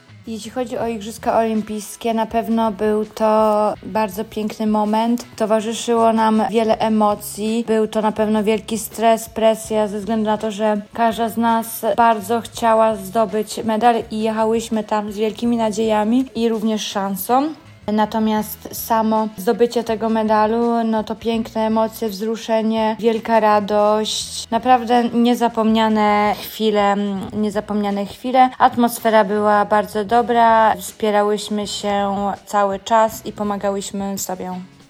Zawody w Paryżu wspomina pierwsza z wymienionych zawodniczek.